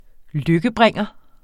Udtale [ -ˌbʁεŋˀʌ ]